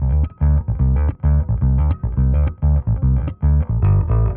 Index of /musicradar/dusty-funk-samples/Bass/110bpm
DF_PegBass_110-D.wav